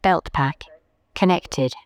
beltpack_connected.wav